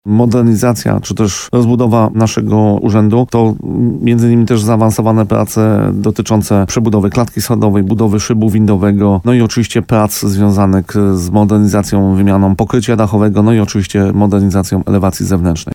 – Przejazd ulicą Kilińskiego będzie częściowo ograniczony – informuje burmistrz Paweł Fyda. Gość programu Słowo za Słowo na antenie RDN Nowy Sącz wyjaśniał, że utrudnienia związane są z tym, że remont urzędu miasta wchodzi w nowy trzeci etap.